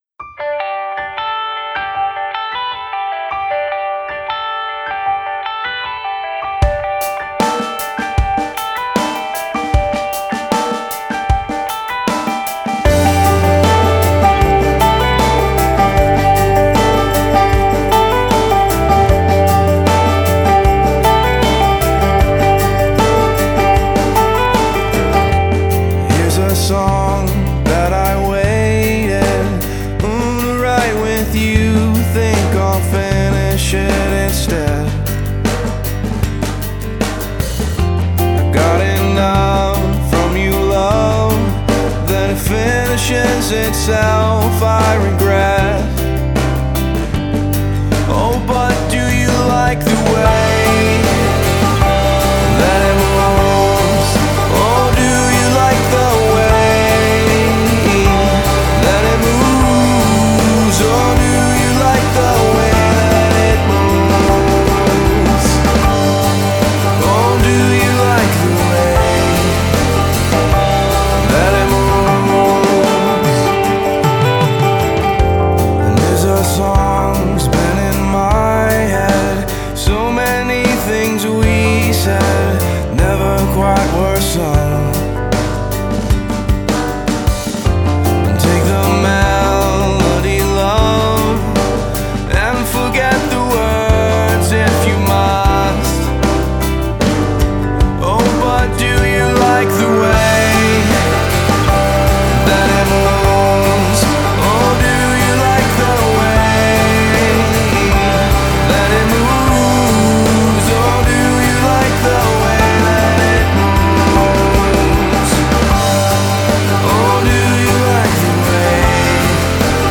are hooky with just a hint of darkness
has a great build up, plateauing into a solid poprock gem